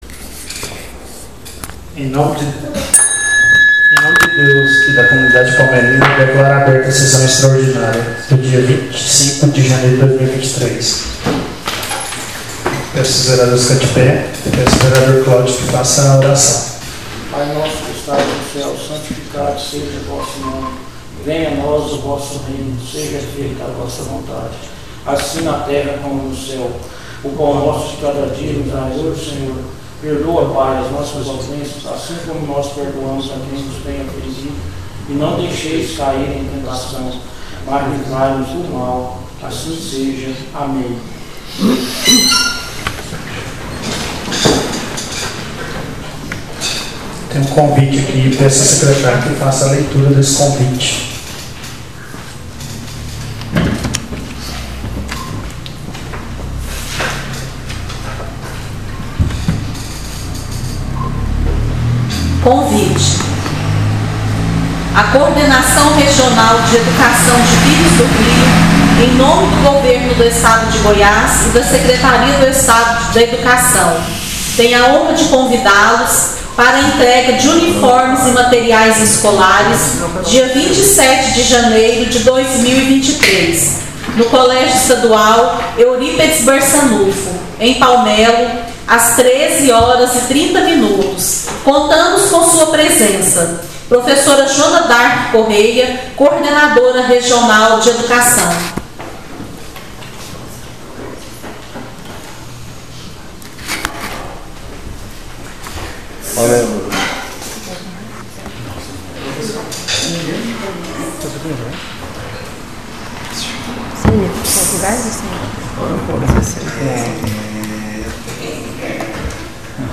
SESSÃO EXTRAORDINÁRIA DIA 25/01/2023